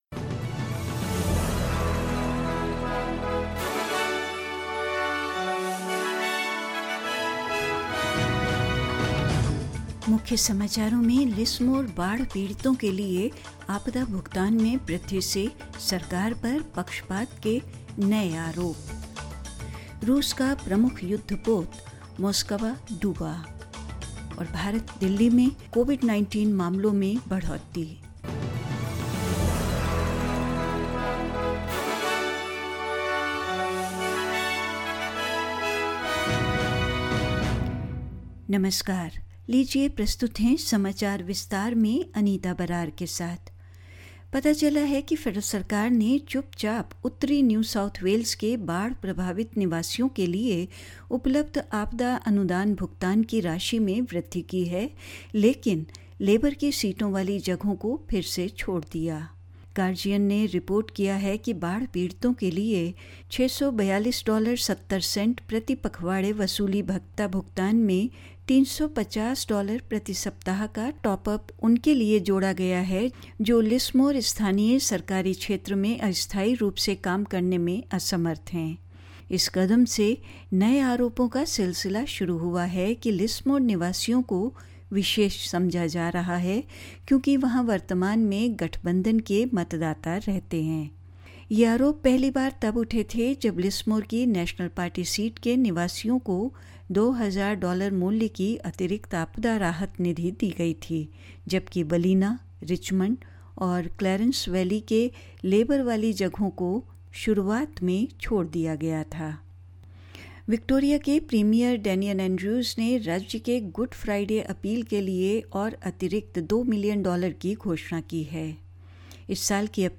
In this latest Hindi bulletin: Increases to disaster payments for Lismore flood victims sparks new accusations of the government playing favourites; Russia's flagship war vessel Moskva sinks; Golfing legend Jack Newton has died aged 72 and more news.